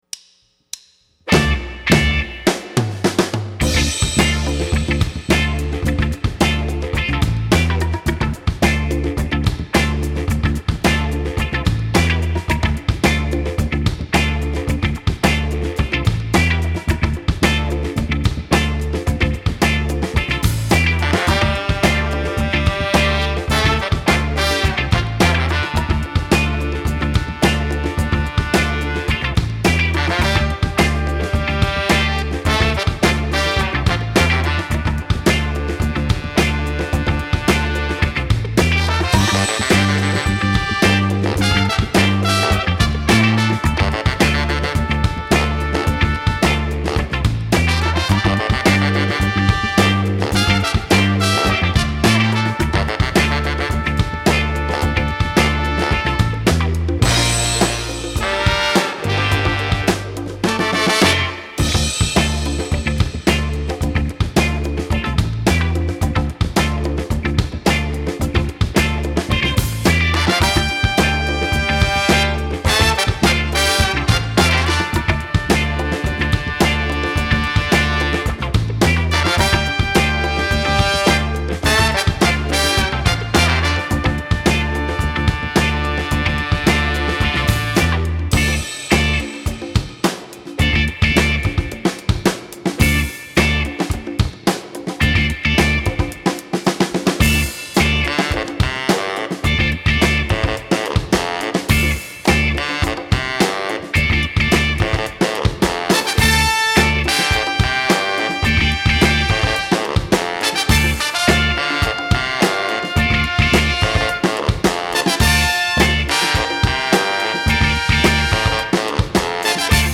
Фанк а-ля 70-е